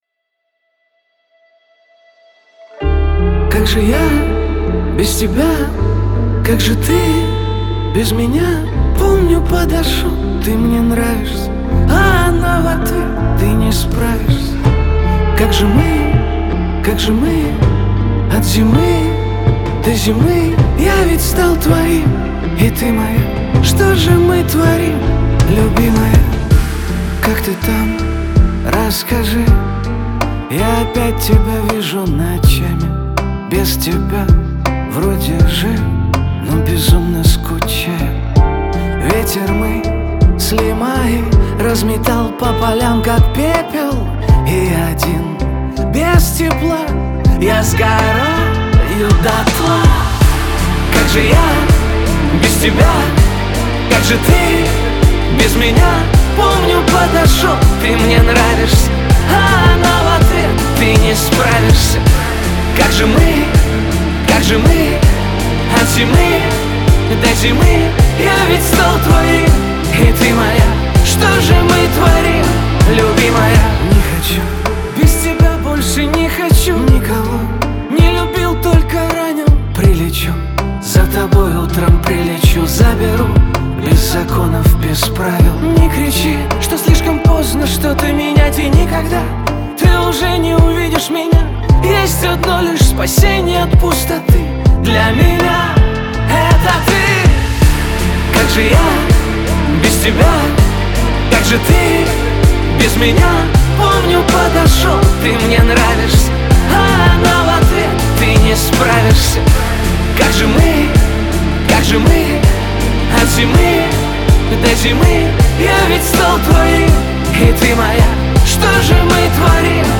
ХАУС-РЭП , грусть
эстрада